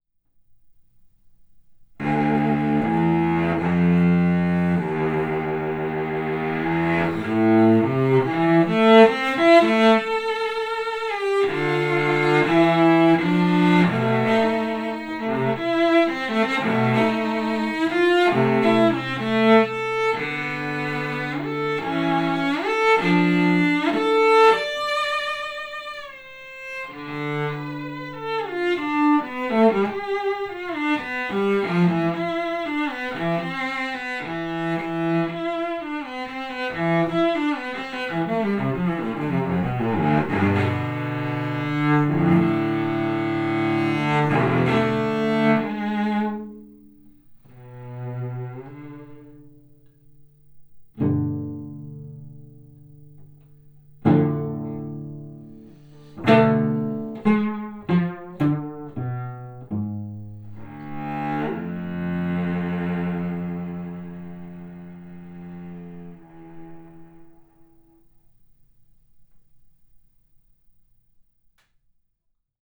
Klangvergleich gespielt von Maximilian Hornung
Cello B:
Cello B: Giovanni Battista Guadagnini anno 1751